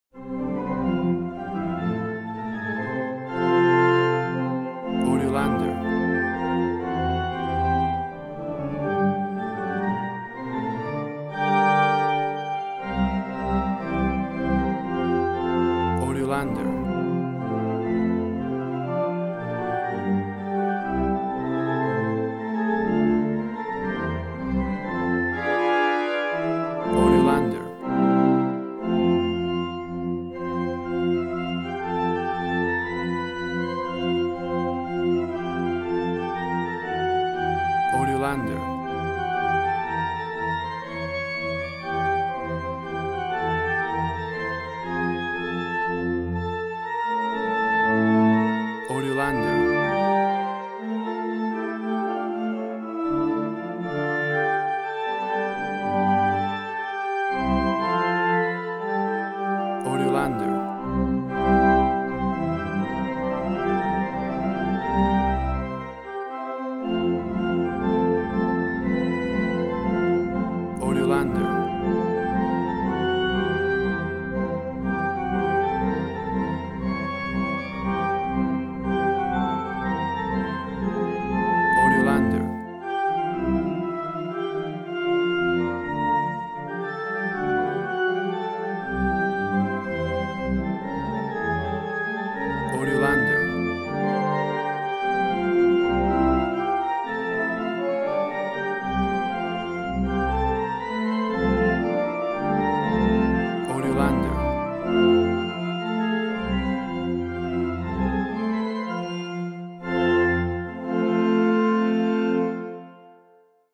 A vibrant and heartwarming church organ version
WAV Sample Rate: 16-Bit stereo, 44.1 kHz